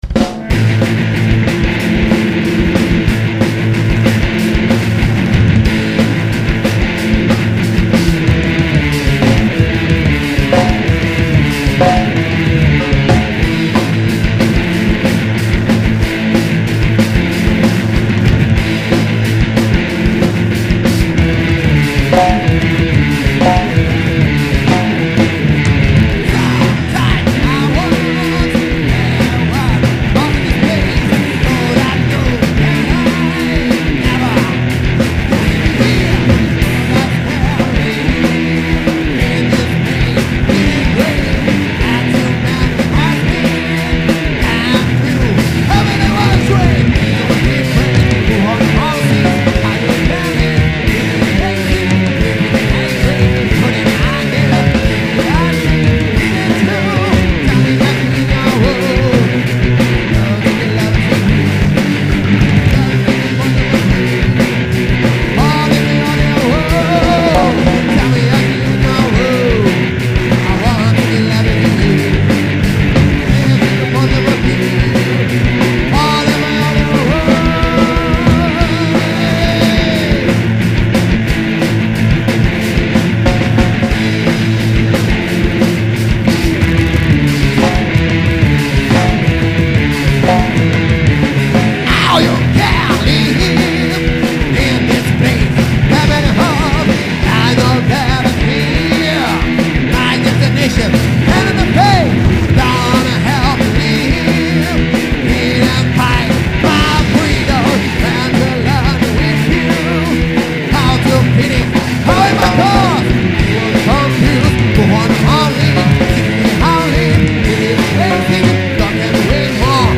Live at Toca do Rock